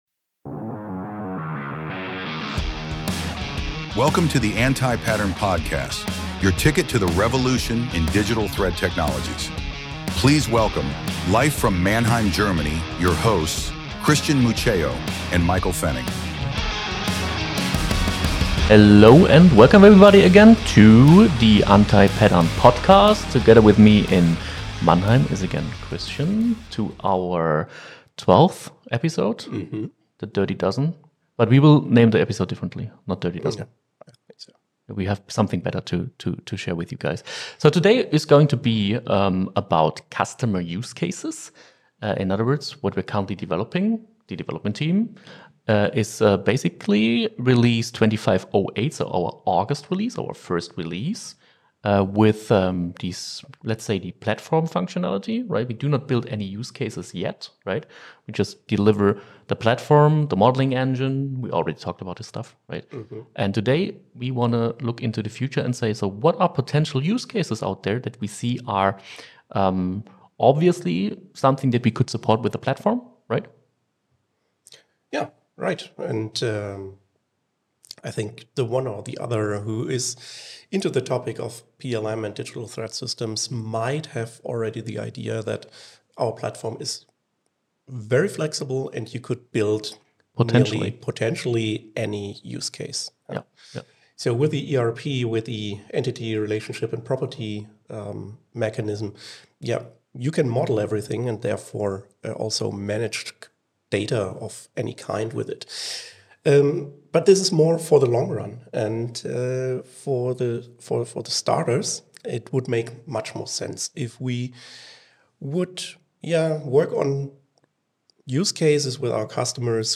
Forget vague promises and bloated roadmaps—this conversation is all about practical, impactful, and early-stage results that actually move the needle. We’ve identified five foundational use cases that can be solved with our platform—even in the earliest phases of a project.